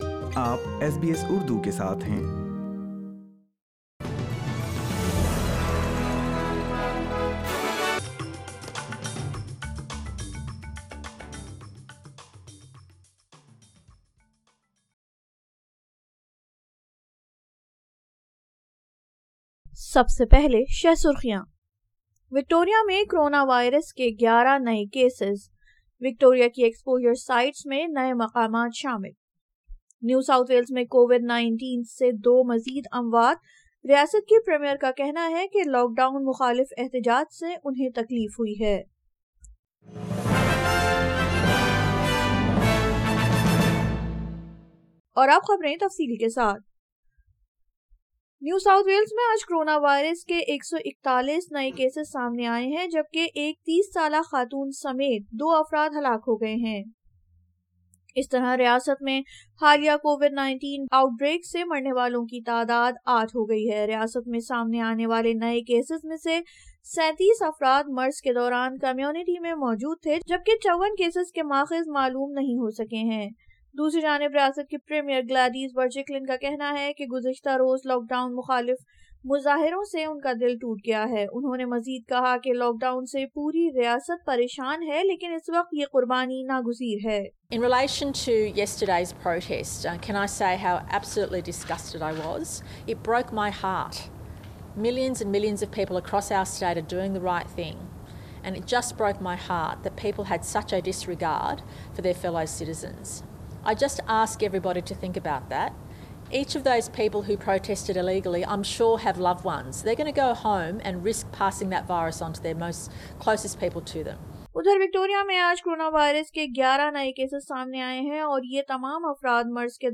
SBS Urdu News 25 July 2021